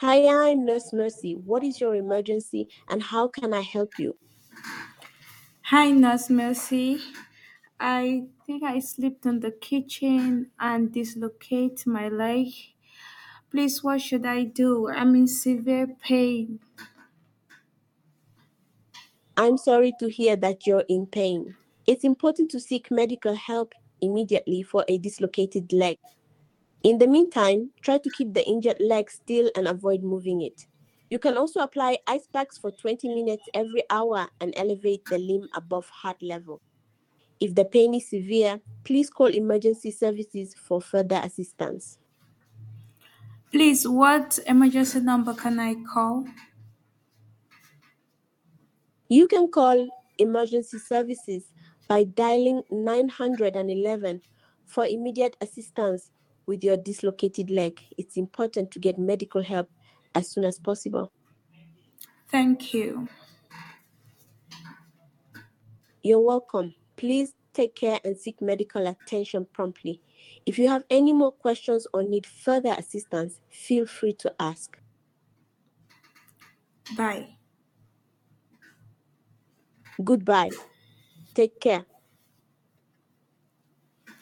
subscription-based army of call center voice agents
with 80+ African voices.